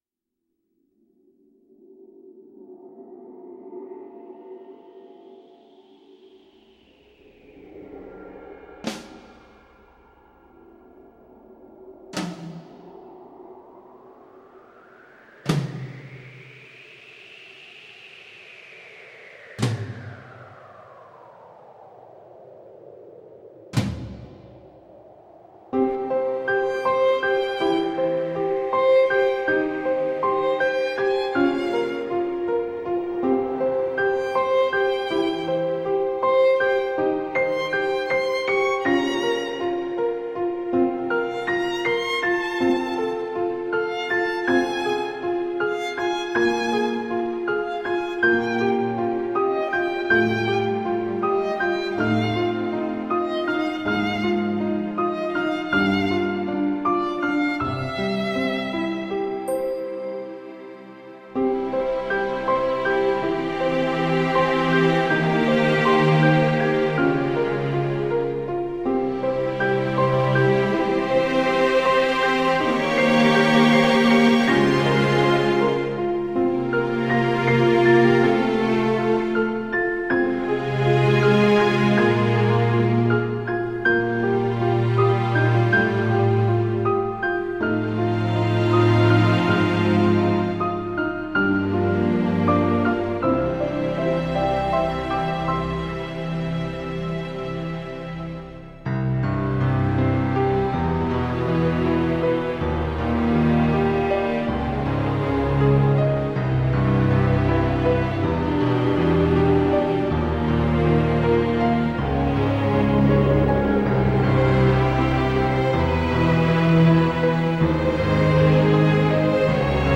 Tagged as: Electronica, Orchestral